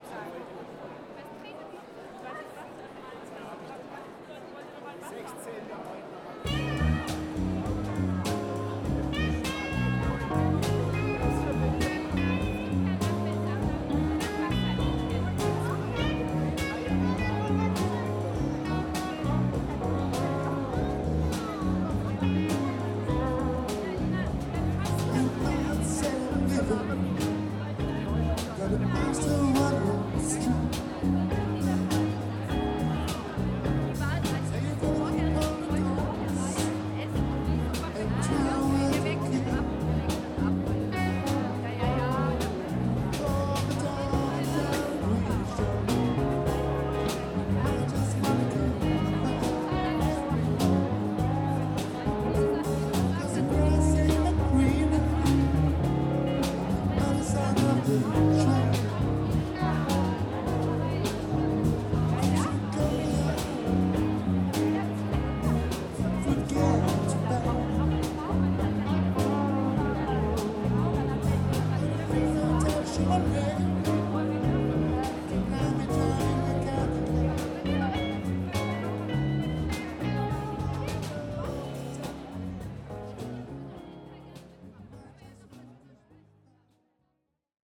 Strasbourg, Arte - CCM41 / CCM8 / MKE2 / SD664